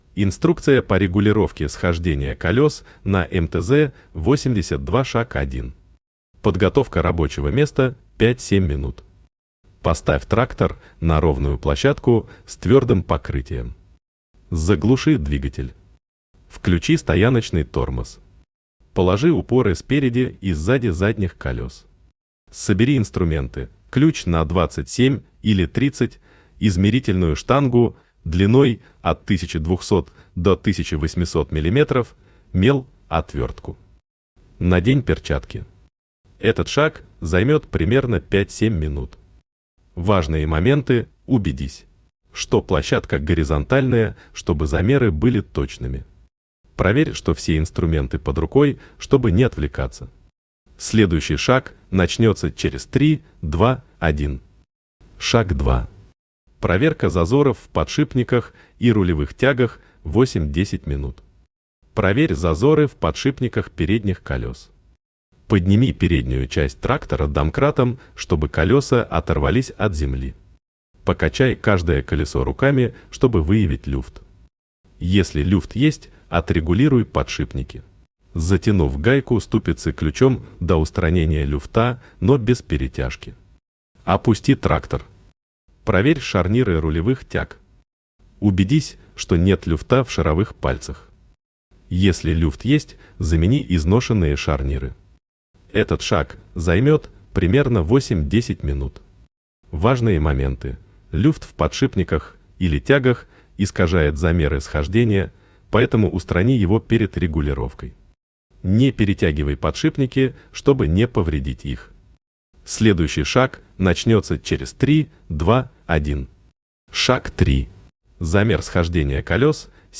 Аудио инструкция по регулировке схождения колёс на МТЗ-82